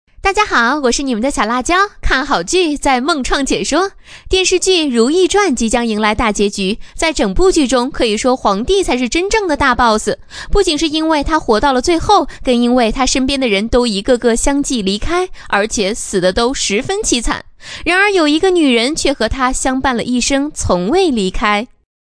【女58号短视频】影视解说（甜美调皮）